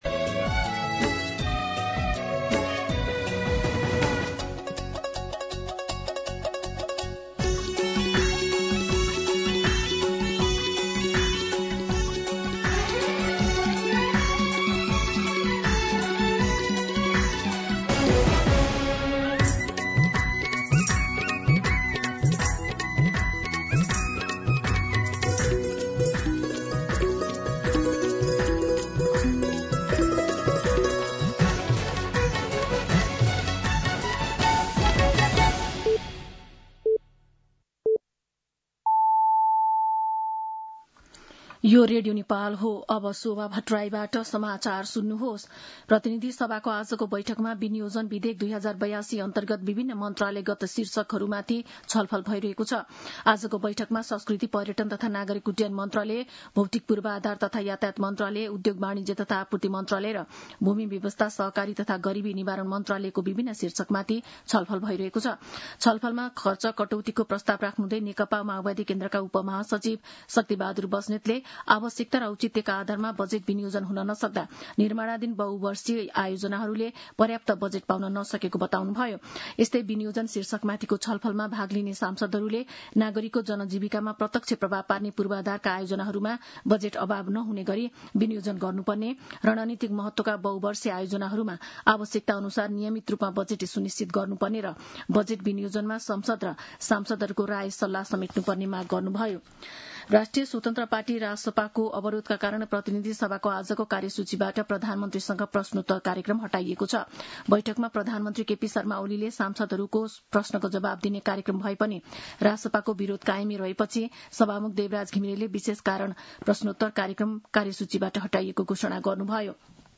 दिउँसो ४ बजेको नेपाली समाचार : ६ असार , २०८२